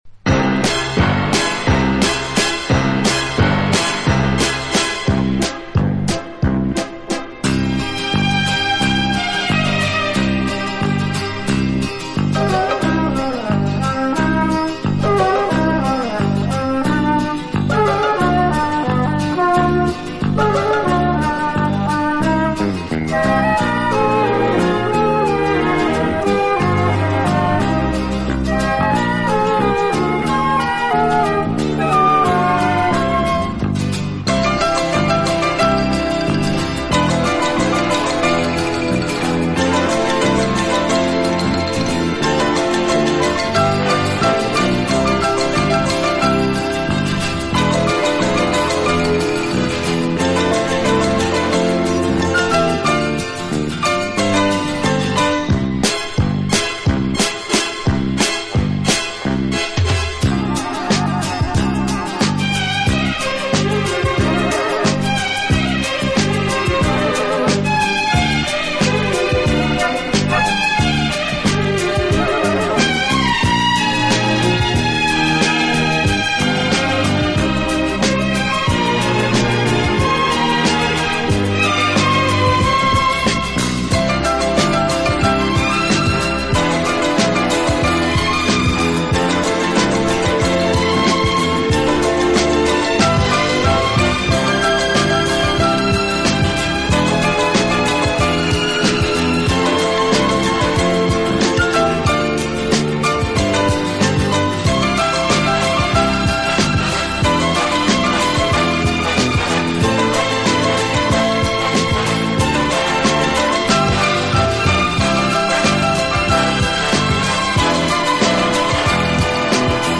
体，曲风浪漫、优雅，令人聆听時如感轻风拂面，丝丝柔情触动心扉，充分领略